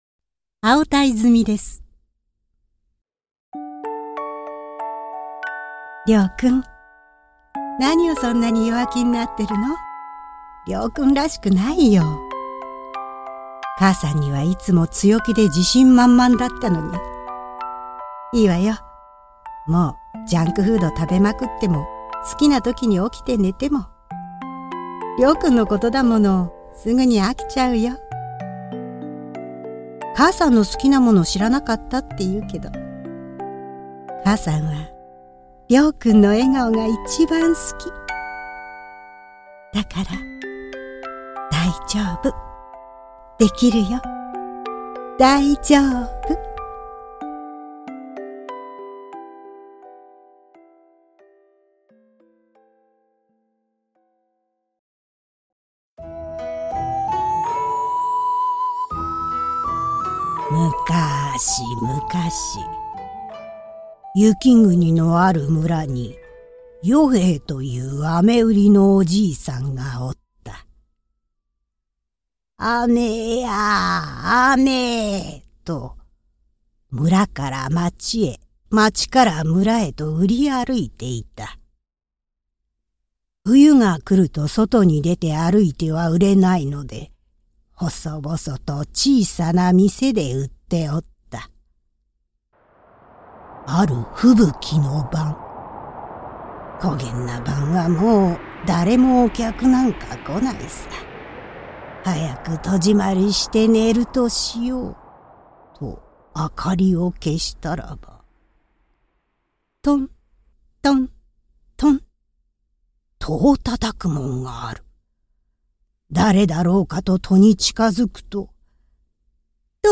高い表現力の落ち着いた中低音